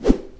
pole_swing.mp3